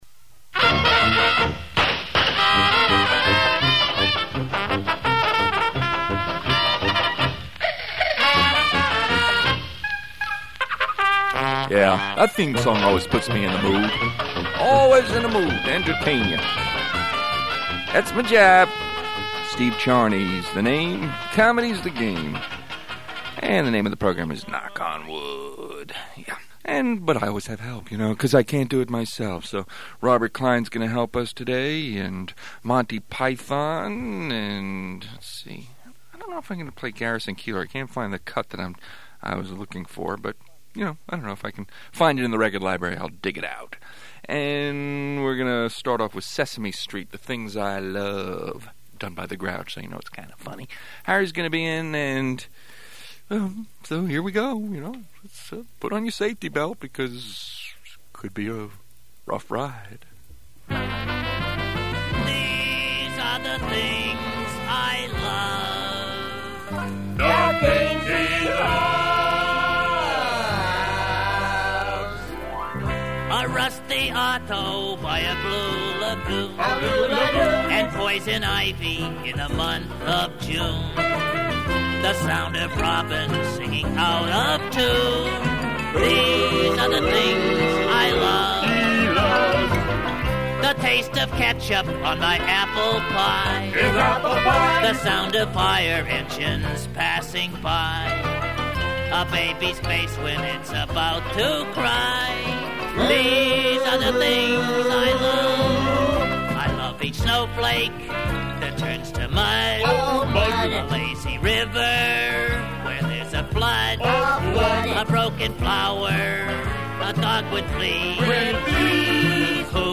Comedy Show